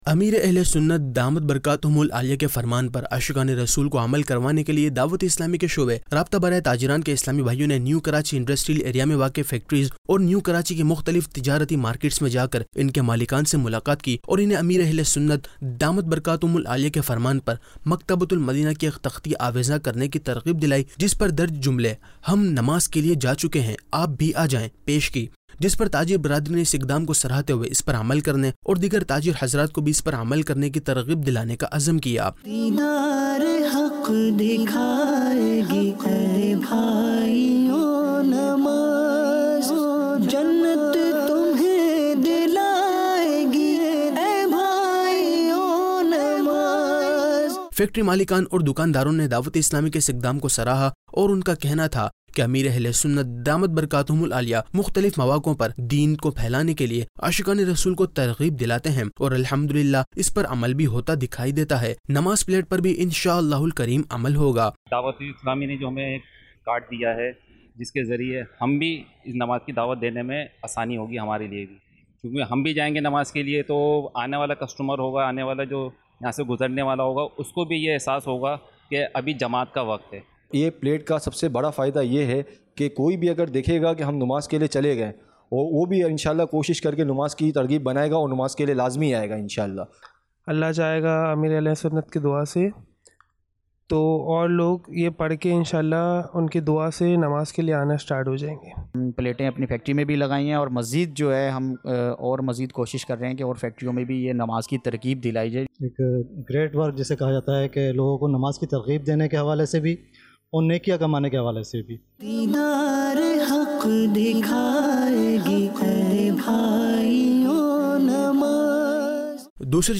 News Clips Urdu - 17 August 2023 - Shoba Rabta Barai Tajiran Kay Islami Bhaiyon Ki Mukhtalif Factory Aur Dukan Malikan Say Mulaqat Aug 24, 2023 MP3 MP4 MP3 Share نیوز کلپس اردو - 17 اگست 2023 - شعبہ رابطہ برائے تاجران کے اسلامی بھائیوں کی مختلف فیکٹری اور دکان مالکان سے ملاقات